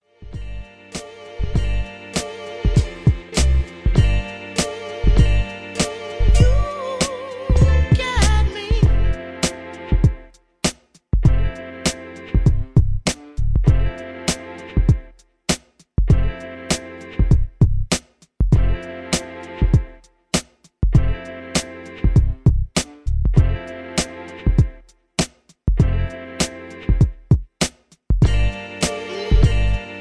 HipHop laced with a soul sample.